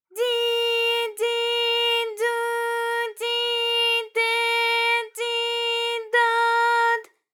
ALYS-DB-001-JPN - First Japanese UTAU vocal library of ALYS.
di_di_du_di_de_di_do_d.wav